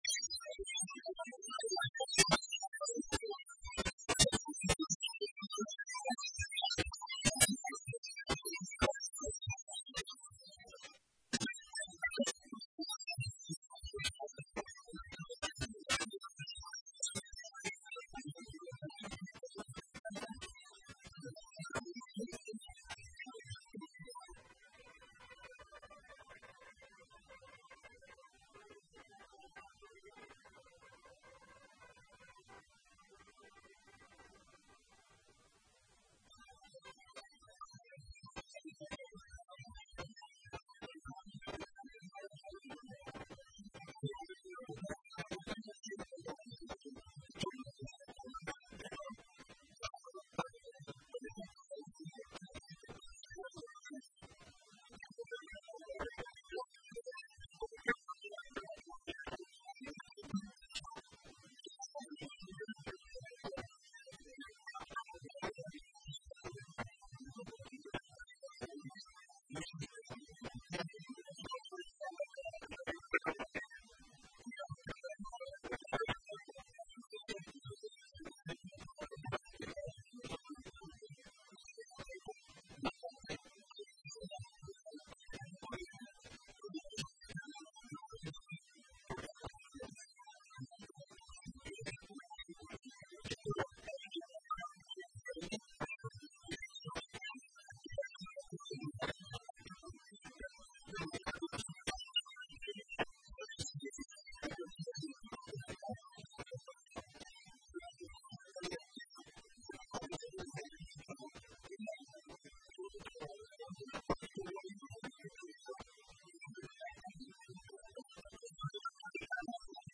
Análisis Político - Momento Grapa como Titularon Los Oyentes :: Radio Federal Bolívar